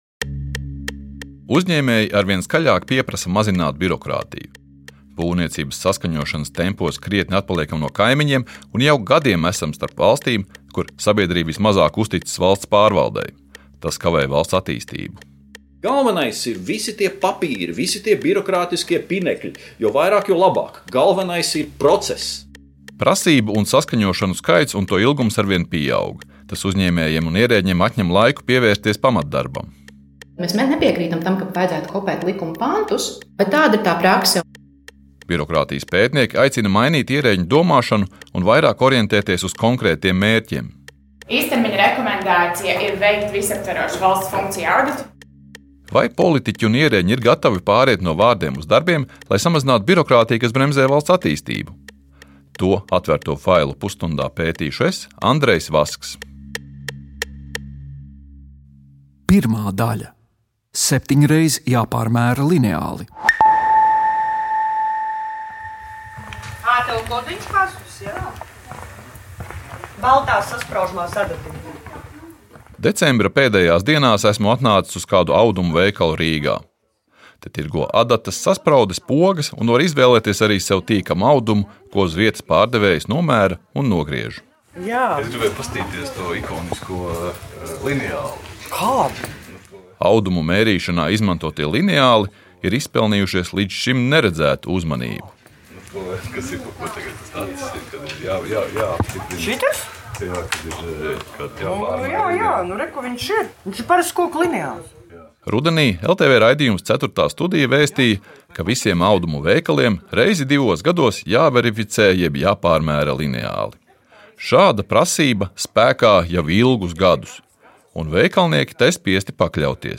No politikas līdz biznesam, par sociālo dzīvi un krimināldrāmām – pētnieciskais raidījums “Atvērtie faili” iedziļinās mūsu laika svarīgākajos notikumos. Katrs raidījums ir dokumentāls audiostāsts par procesiem un cilvēkiem, kas veido mūsu sabiedrisko dzīvi.